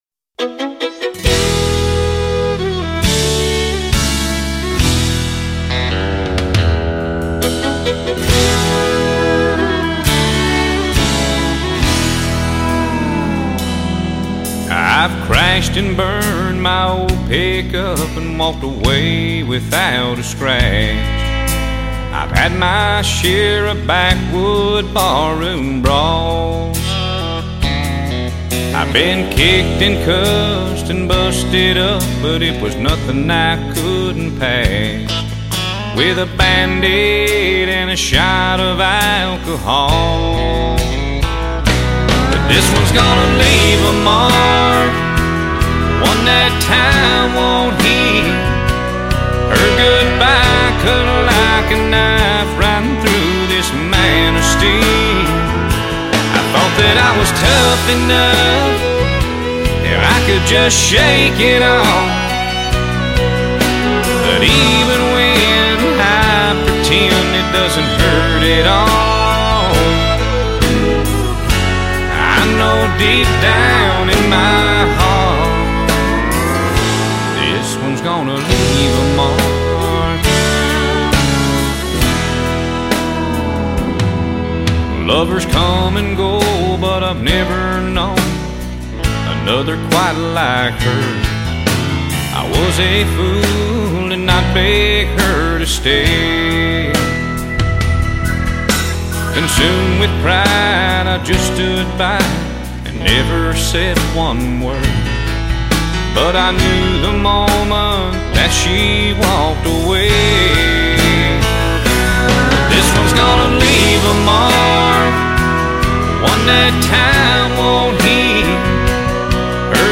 Male
Singing
Singing Demo